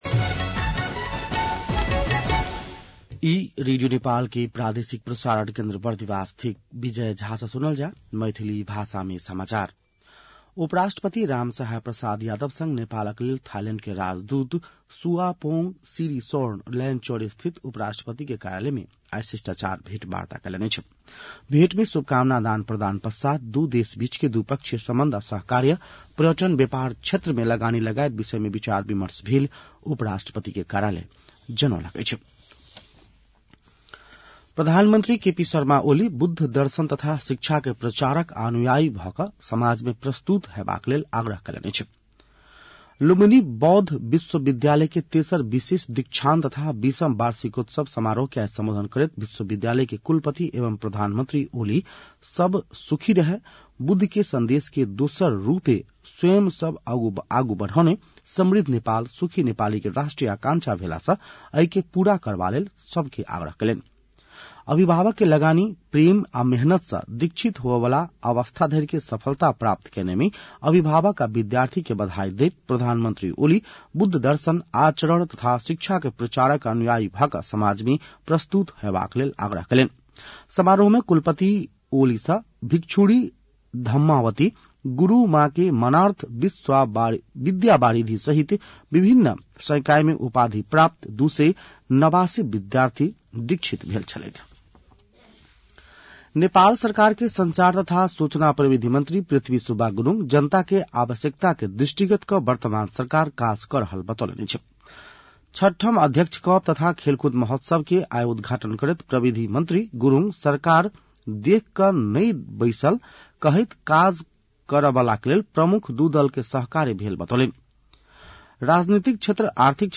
मैथिली भाषामा समाचार : १५ मंसिर , २०८१
Maithali-news-8-14.mp3